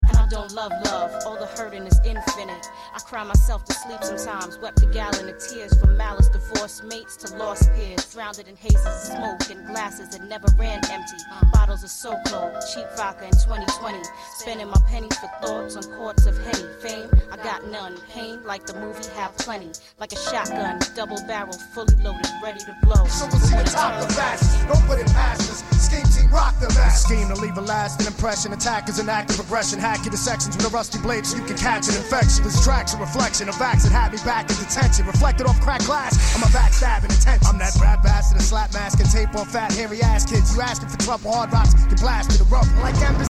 hardcore hip-hop